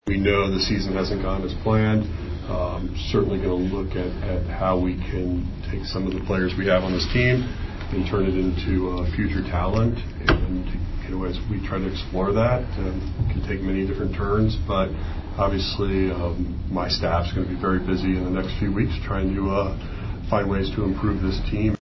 Cardinals president John Mozeliak met with the media prior to the series opener against Miami on Monday afternoon. With the club 11 games out of first place, Mozeliak will look at the trade deadline as a way to improve the club for 2024 and beyond.